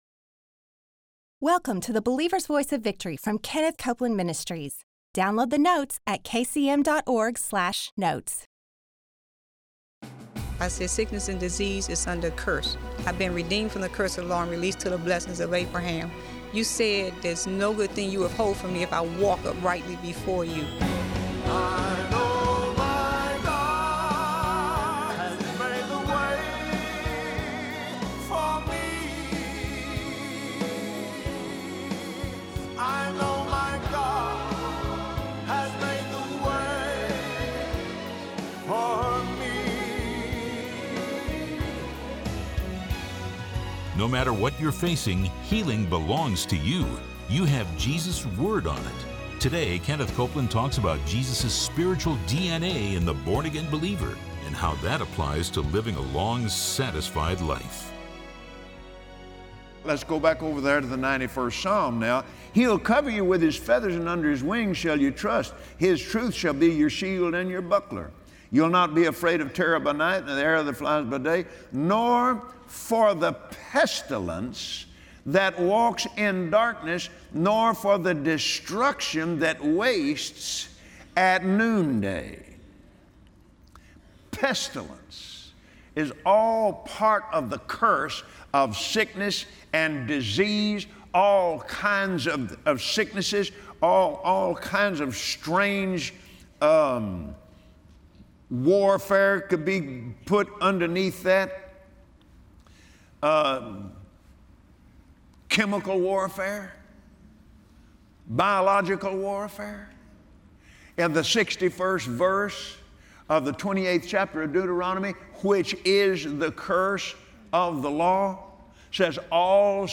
Believers Voice of Victory Audio Broadcast for Thursday 05/04/2017 Healing belongs to you! Watch Kenneth Copeland on Believer’s Voice of Victory describe how you have the same spiritual DNA as Jesus, and how to feed on the Word of God to live a long life.